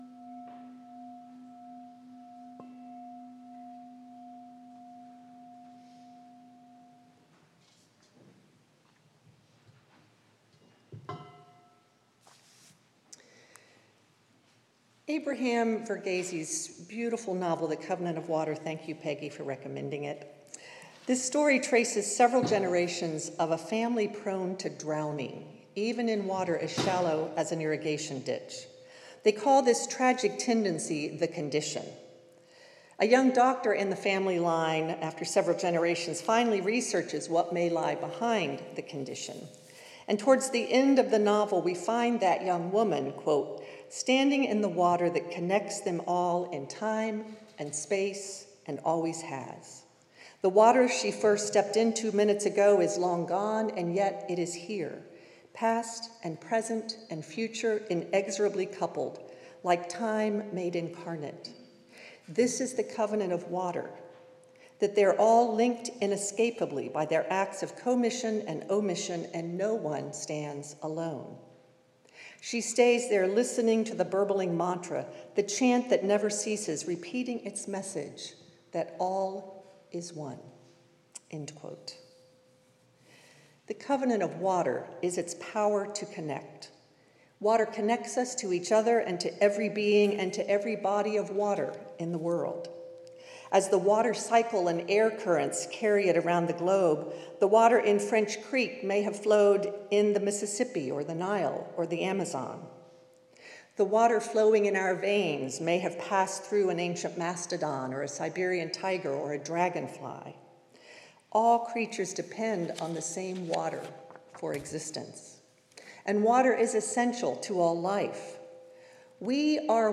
As we return to worship in the sanctuary, this service focuses on water and continues a series of services on the elements of air, water, fire, and earth, which we started in June. Focusing on how water connects us to each other and every living thing, our service with include a Water Ritual, or Ingathering, so plan to bring a small amount of water to contribute to a common vessel.